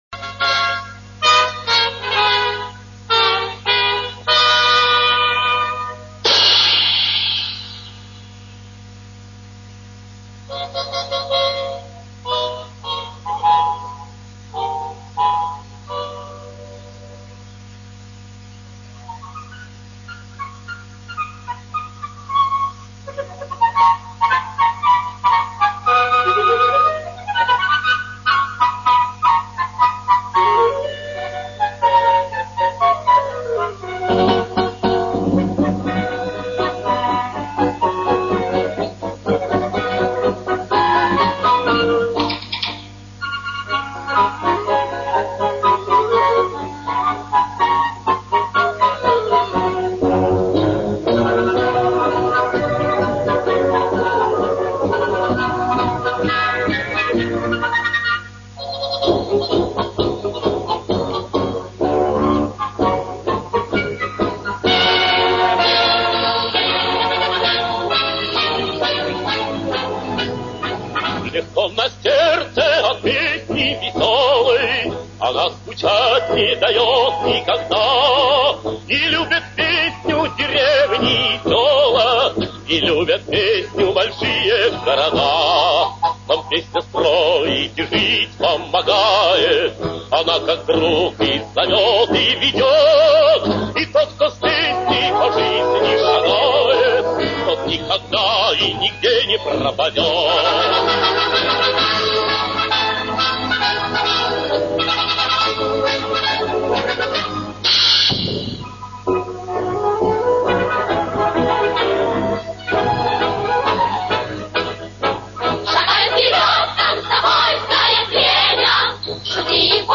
Бодрый марш